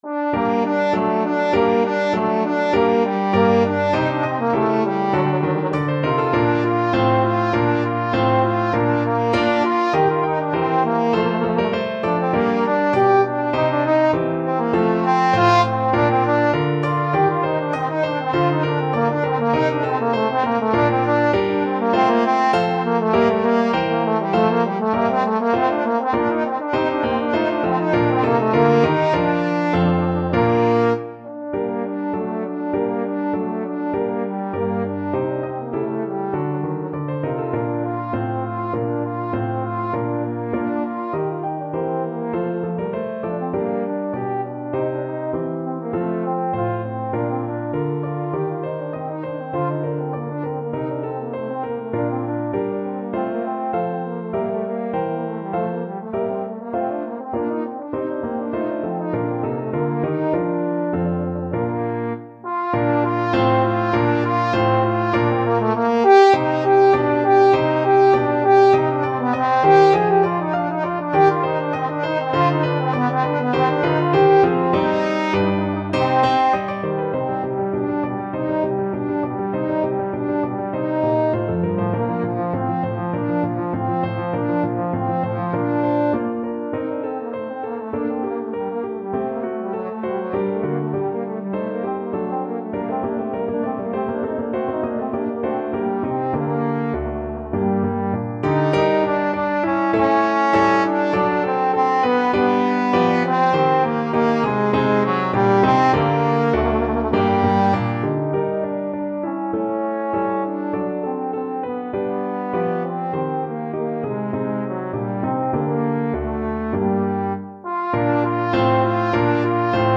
Free Sheet music for Trombone
Trombone
4/4 (View more 4/4 Music)
F4-Ab5
G minor (Sounding Pitch) (View more G minor Music for Trombone )
II: Allegro (View more music marked Allegro)
Classical (View more Classical Trombone Music)